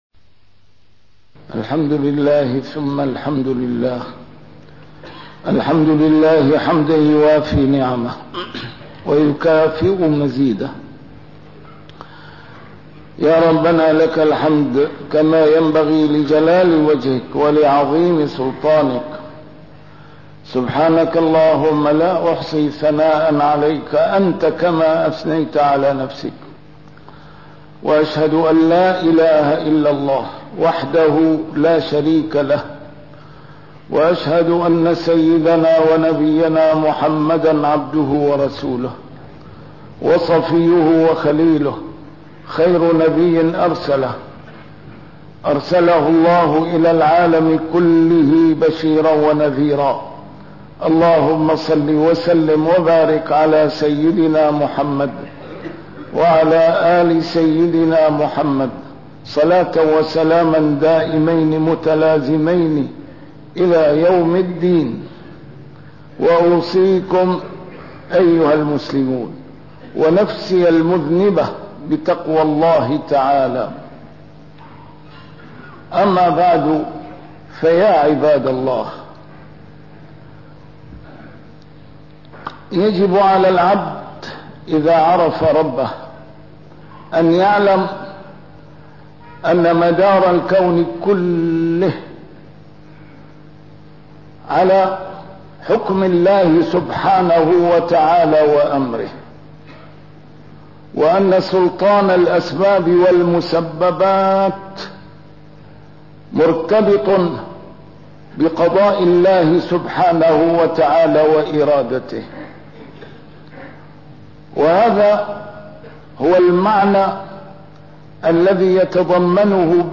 نسيم الشام › A MARTYR SCHOLAR: IMAM MUHAMMAD SAEED RAMADAN AL-BOUTI - الخطب - جلاء المصائب مرهون بالعودة إلى الله عز وجل